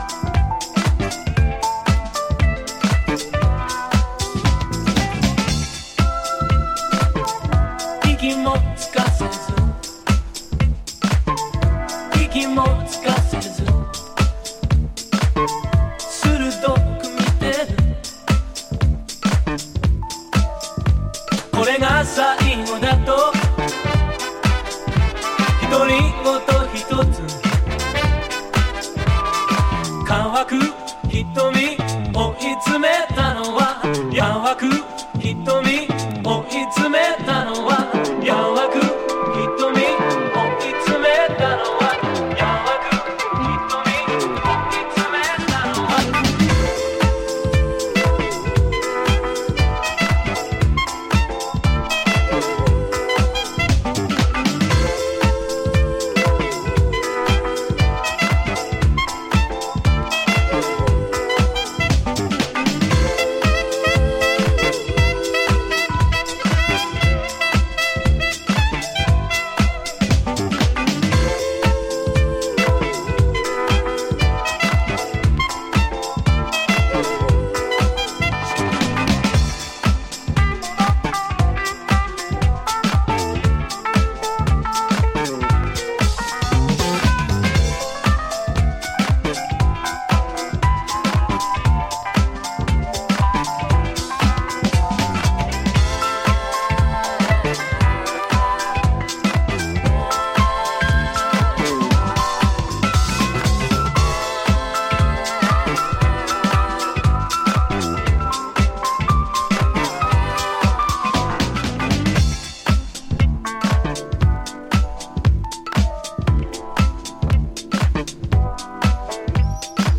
ジャンル(スタイル) DISCO / EDITS